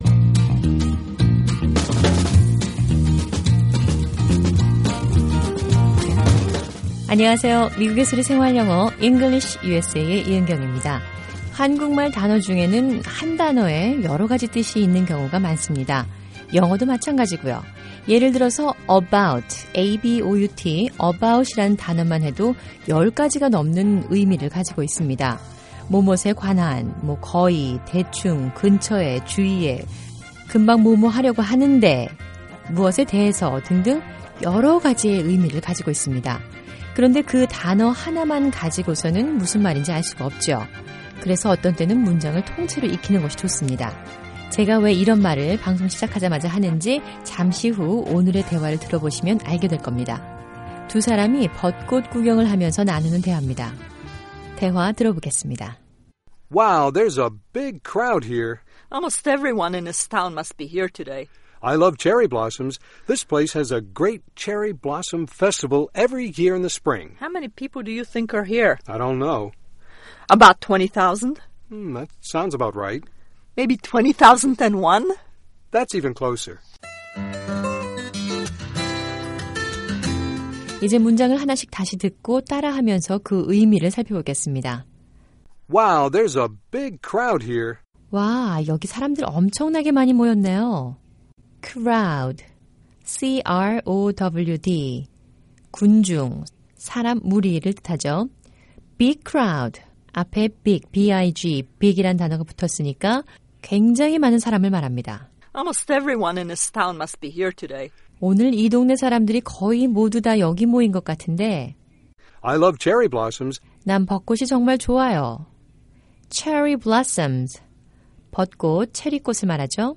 English USA는 일상 생활에서 자주 사용하는 영어 표현을 배워보는 시간입니다. 오늘은 두 사람이 벚꽃 구경을 하면서 나누는 대화입니다.